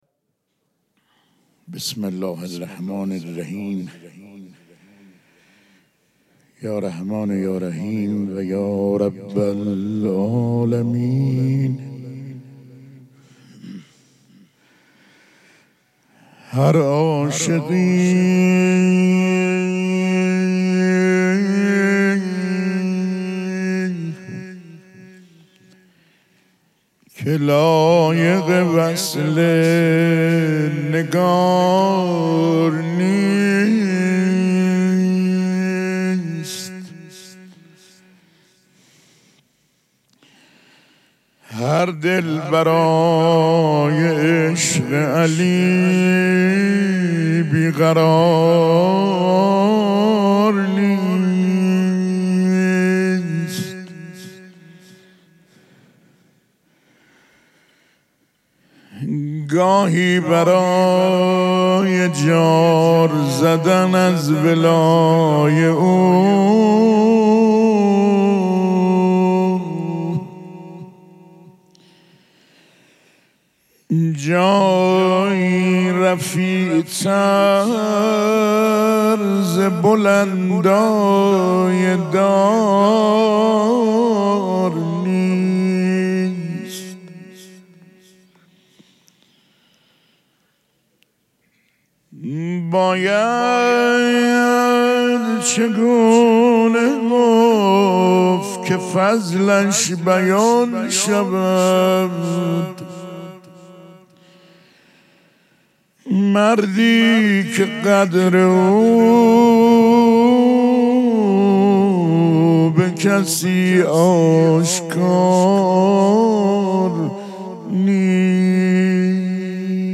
شب چهارم مراسم عزاداری دهه دوم فاطمیه ۱۴۴۶
پیش منبر